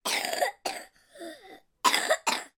Звуки кашля ребенка
Ребенку 3 4 года